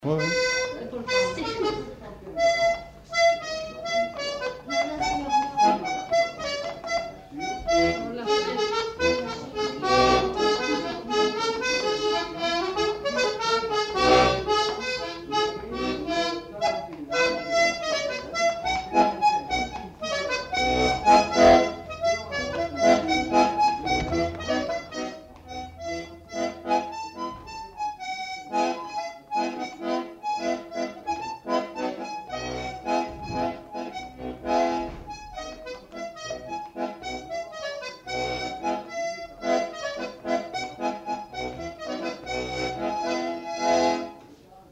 Rondeau
Aire culturelle : Savès
Lieu : Pavie
Genre : morceau instrumental
Instrument de musique : accordéon diatonique
Danse : rondeau